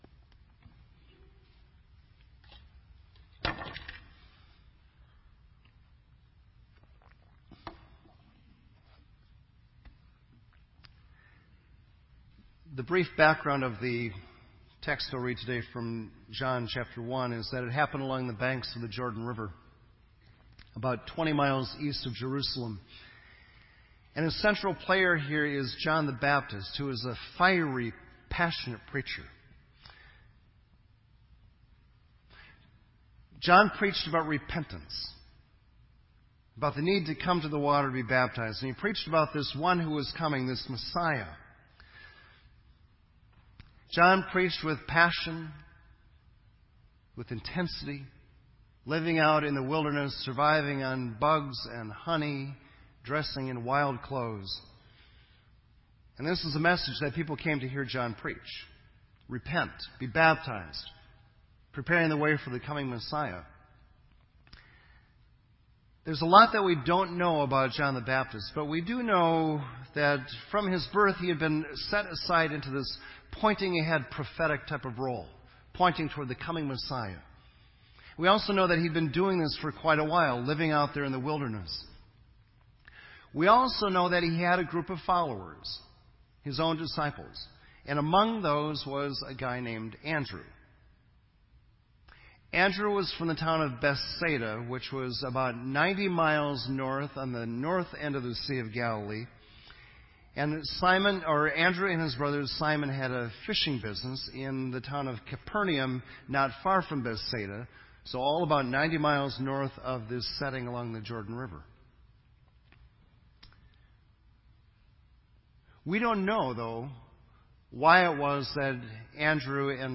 This entry was posted in Sermon Audio on January 11